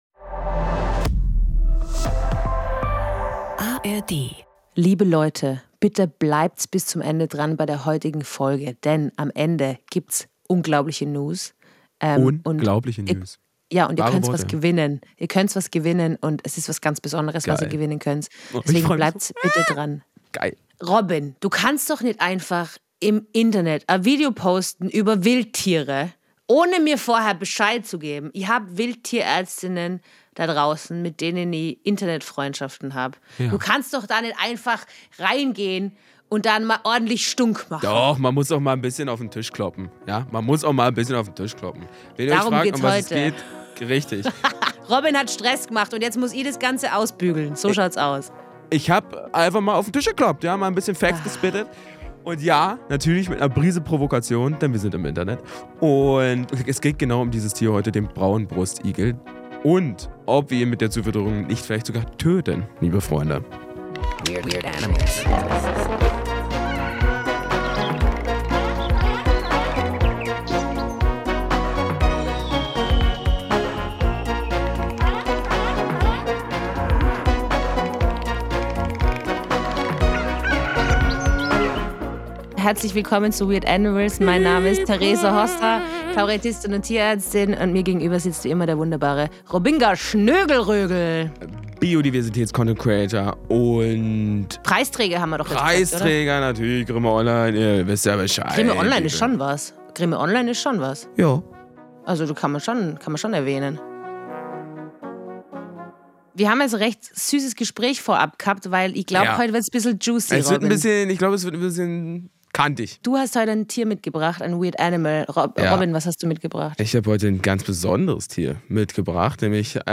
Am Ende erwartet euch eine Diskussion